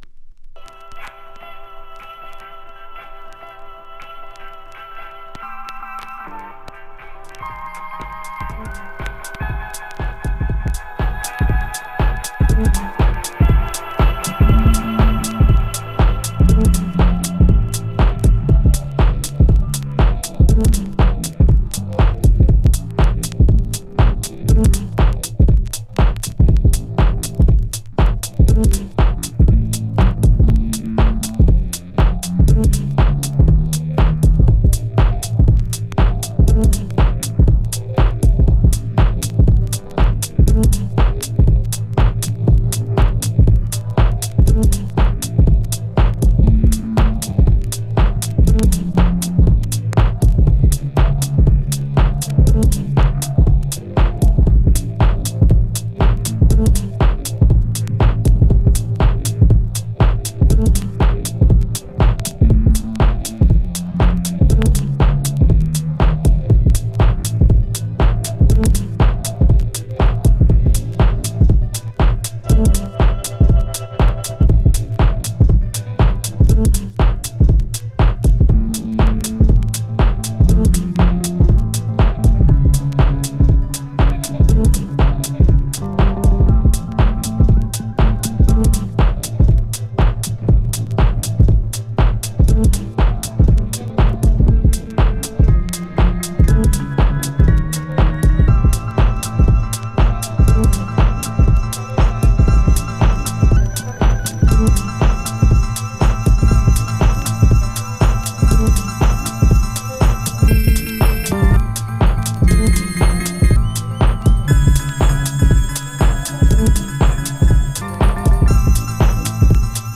2. > TECHNO/HOUSE